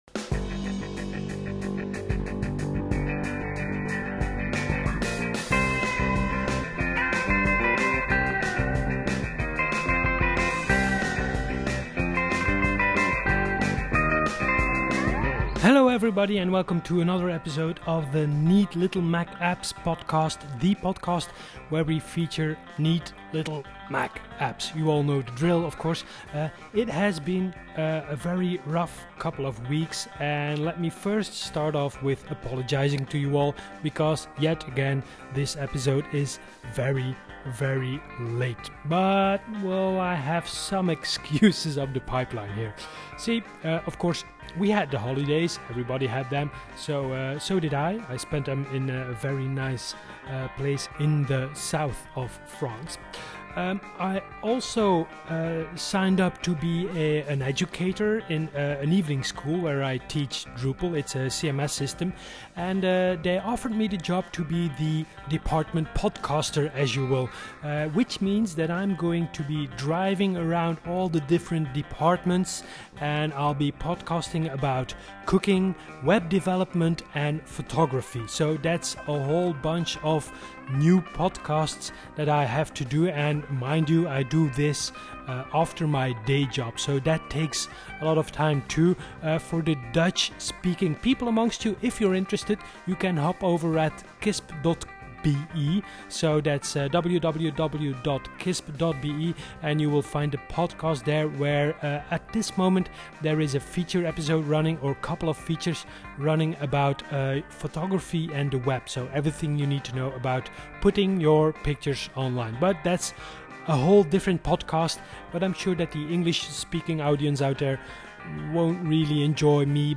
NeatLittleMacApps Genre: Podcast, Interview Year